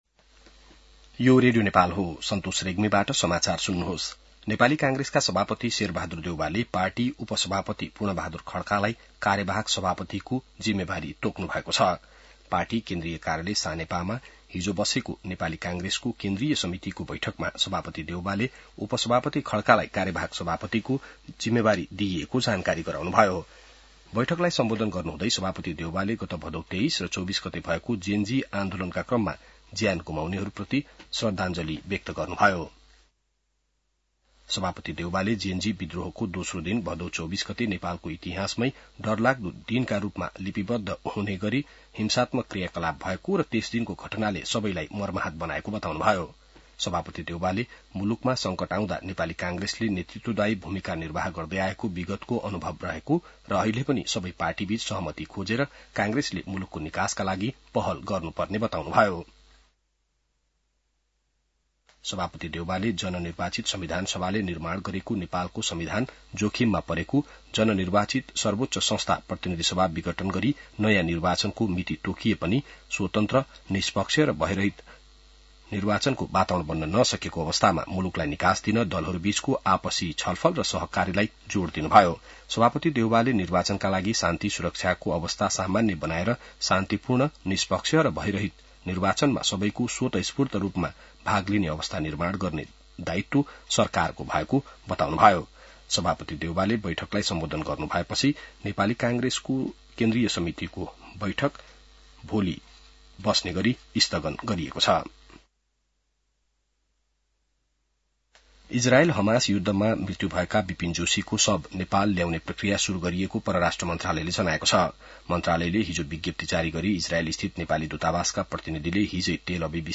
बिहान ६ बजेको नेपाली समाचार : २९ असोज , २०८२